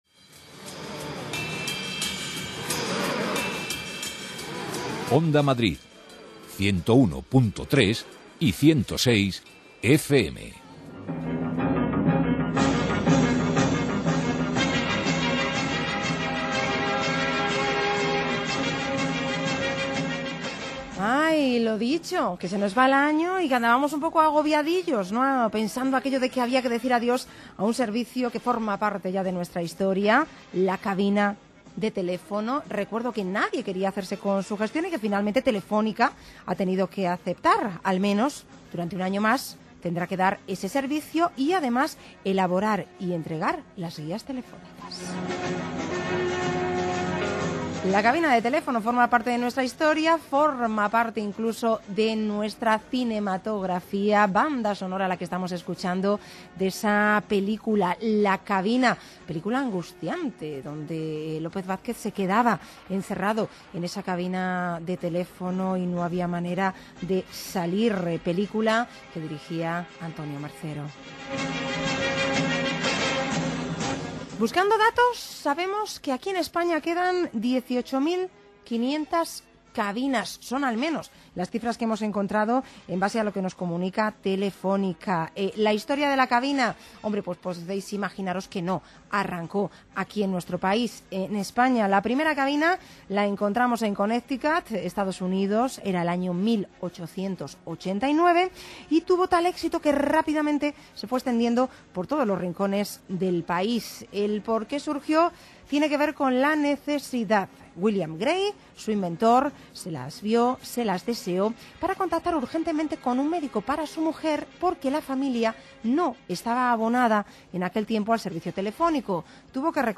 entrevista
en la emisora Onda Madrid